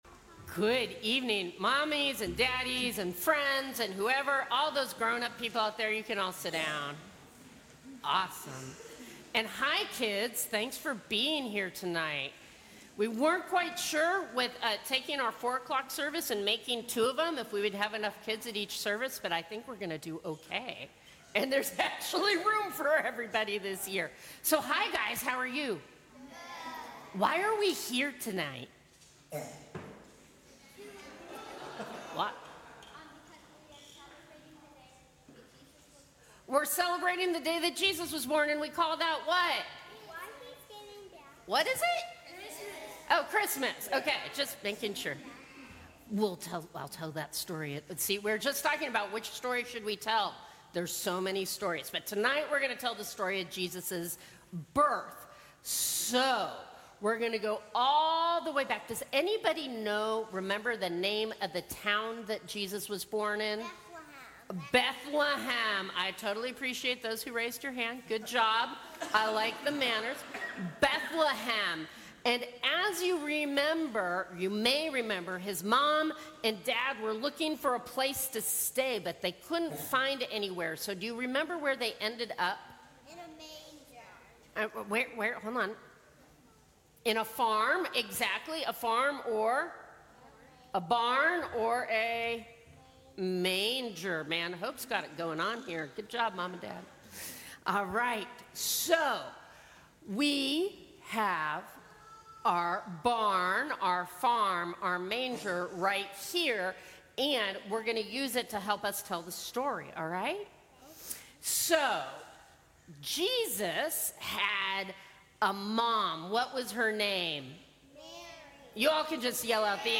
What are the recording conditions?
Christmas Eve Family Service